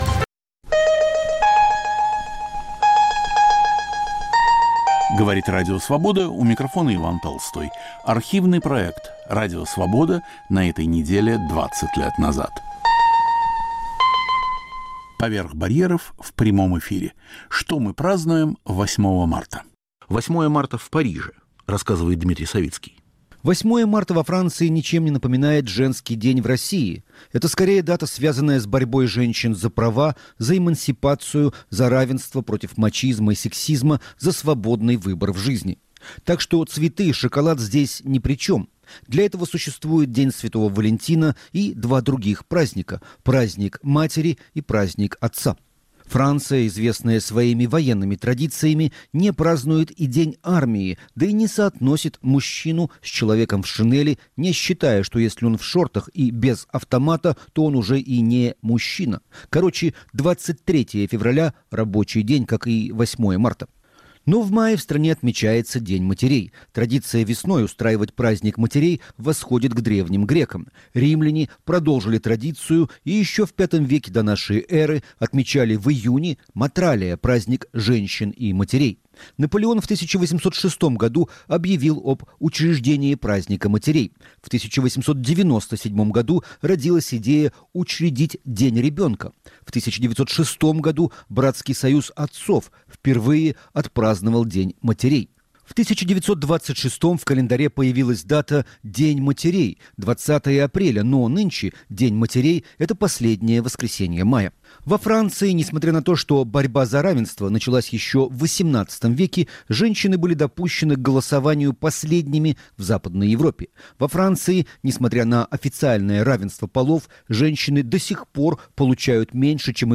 "Поверх барьеров" в прямом эфире. Что мы празднуем 8 марта